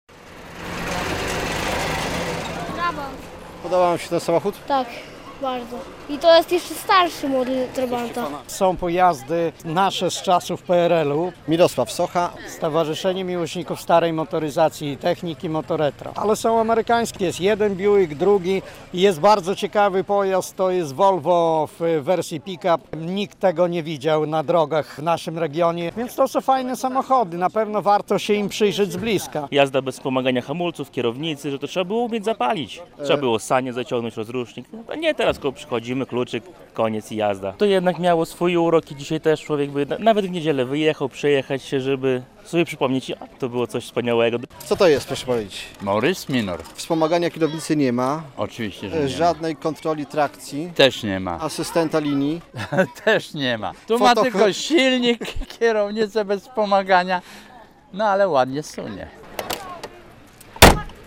Jesienny zlot fanów starej motoryzacji MOTO RETRO - relacja
Wszystko to można było oglądać w niedzielę (22.09) w Białymstoku na jesiennym zlocie fanów starej motoryzacji MOTO RETRO.